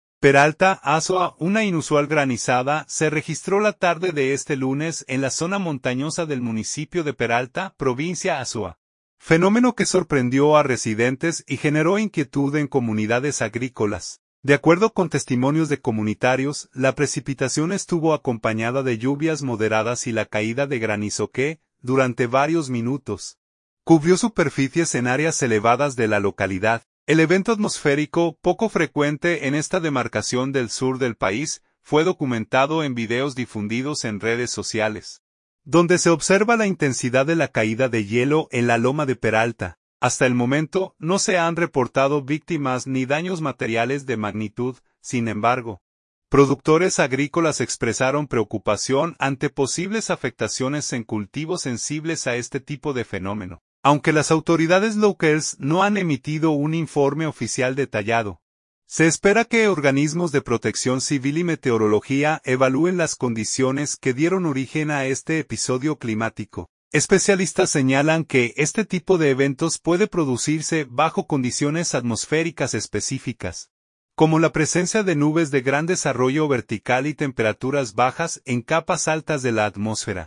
De acuerdo con testimonios de comunitarios, la precipitación estuvo acompañada de lluvias moderadas y la caída de granizo que, durante varios minutos, cubrió superficies en áreas elevadas de la localidad.
El evento atmosférico, poco frecuente en esta demarcación del sur del país, fue documentado en videos difundidos en redes sociales, donde se observa la intensidad de la caída de hielo en la loma de Peralta.